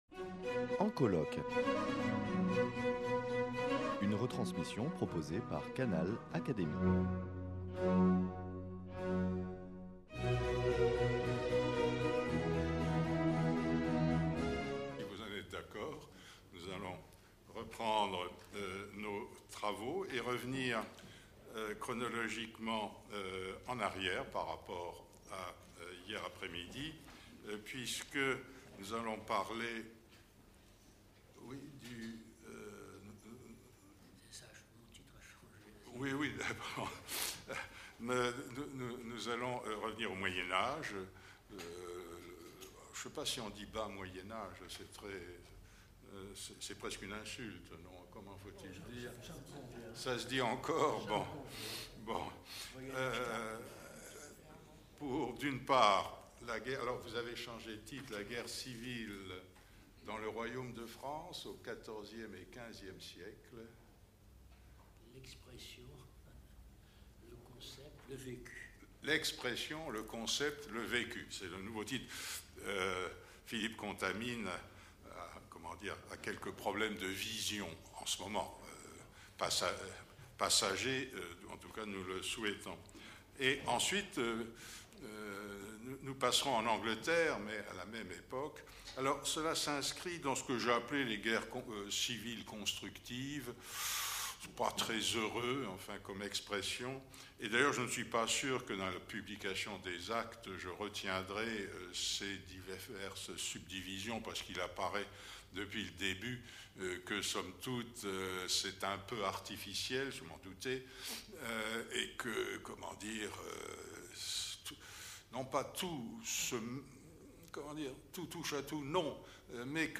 Retransmission du colloque international « La guerre civile » - partie 9